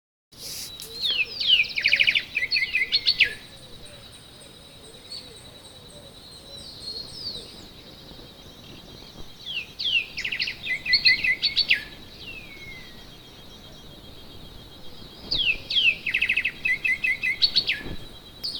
Bluish-grey Saltator (Saltator coerulescens)
Life Stage: Adult
Location or protected area: Parque Natural Municipal Ribera Norte (San Isidro)
Condition: Wild
Certainty: Recorded vocal
pepitero-gris.mp3